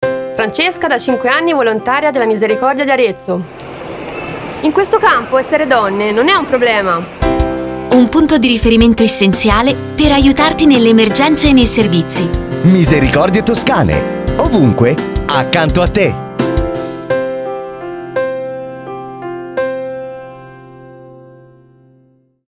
Campagna spot radiofonico Arezzo
170-campagna-spot-radiofonico-arezzo.html